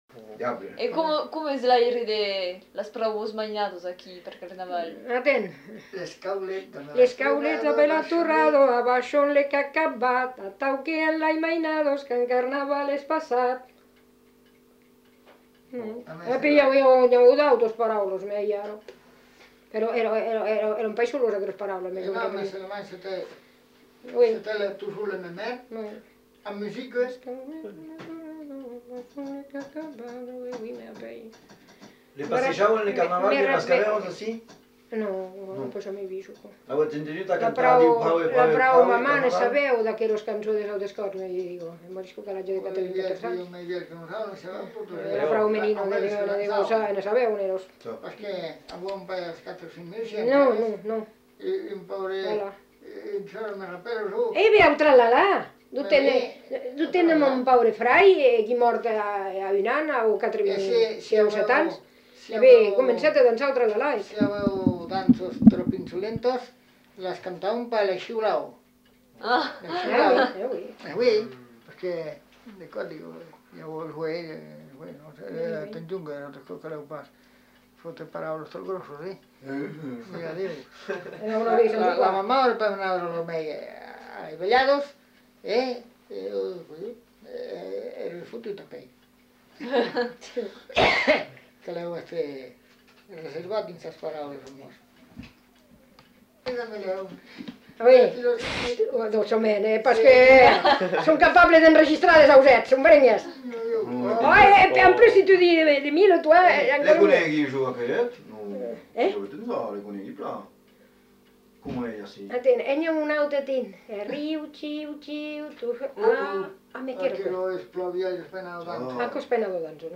Aire culturelle : Lomagne
Genre : chant
Effectif : 1
Type de voix : voix de femme
Production du son : chanté
Classification : chanson de carnaval
Notes consultables : Suit une discussion avec évocation de chants.